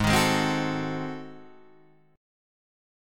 G#7b9 chord